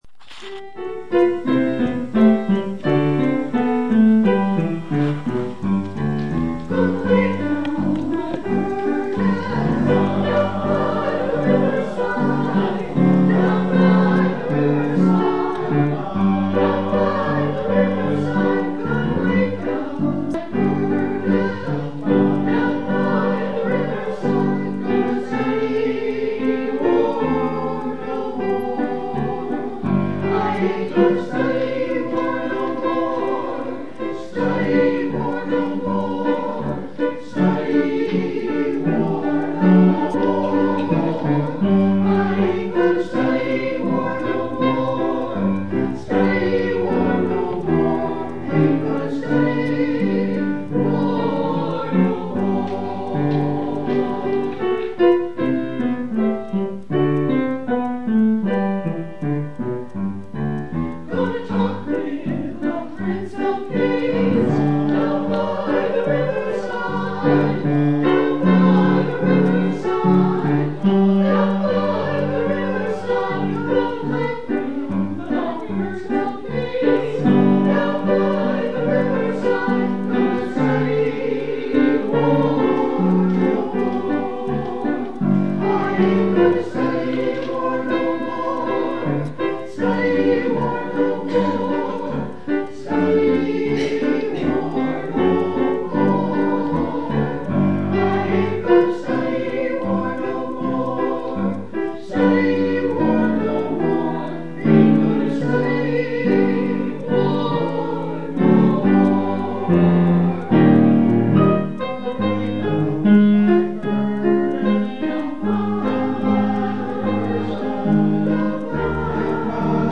Download   Music / Anthem: “Down By the Riverside” – Althouse – 10/16/2016